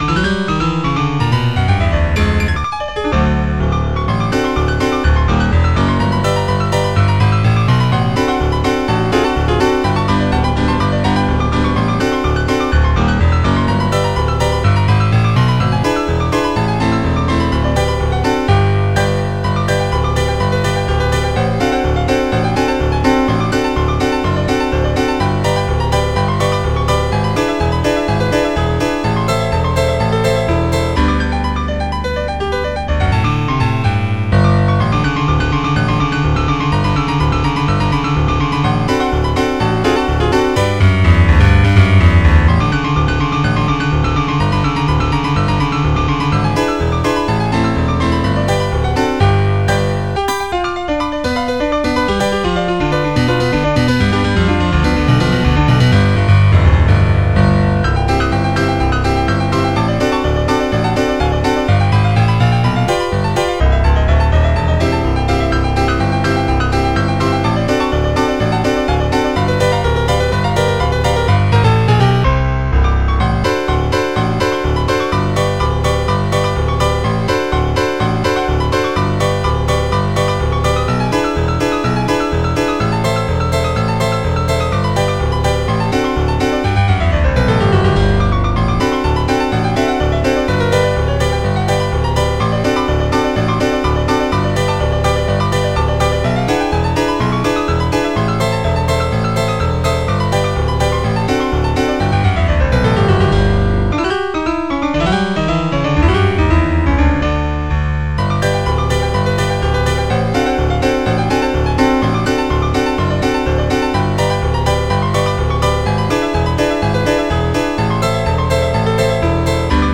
Piano
Arranged for piano by